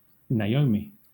Ääntäminen
Southern England
UK : IPA : /naɪˈəʊmi/